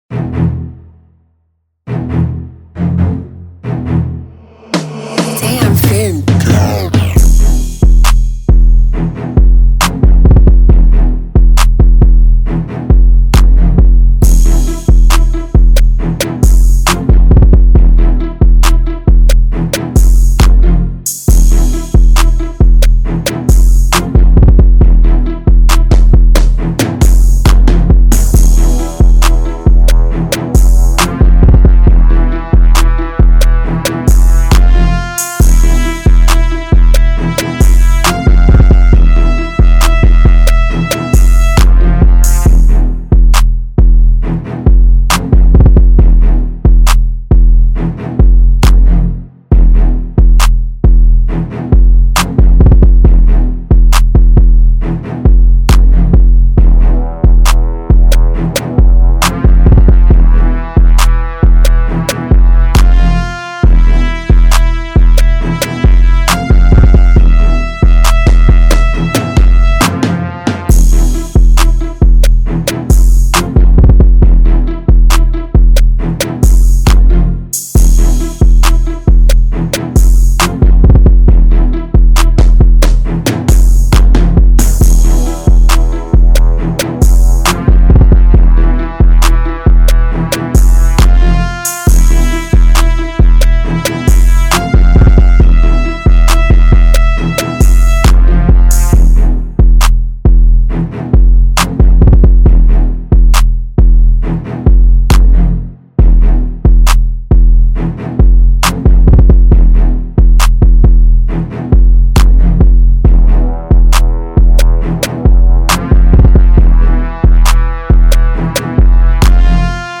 Trap Instrumental